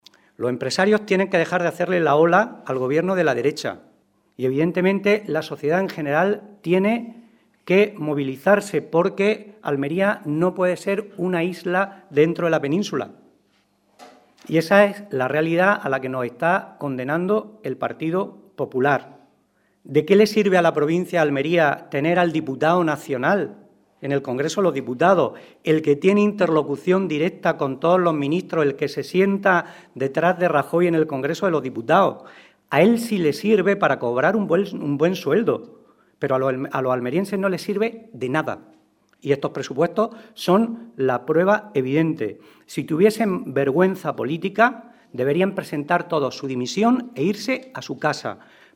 Rueda de prensa que ha ofrecido el PSOE de Almería sobre los Presupuestos Generales del Estado para 2017